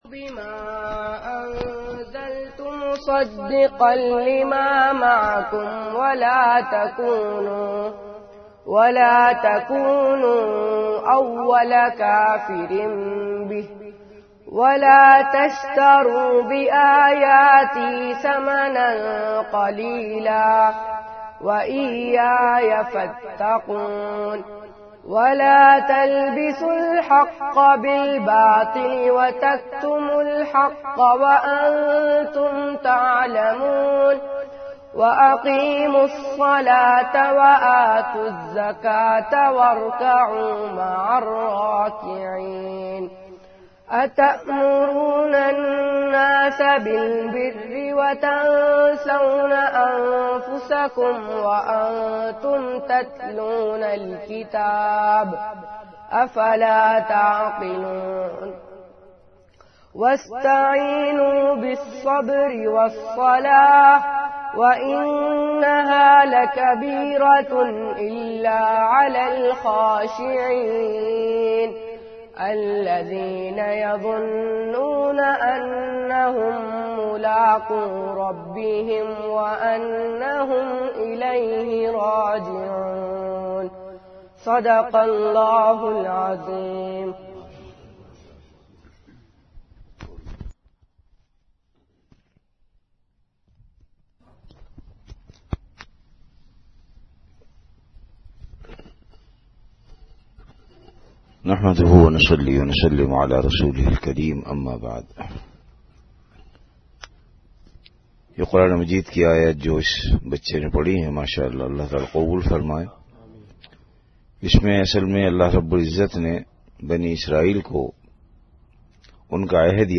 Delivered at Madinah Munawwarah.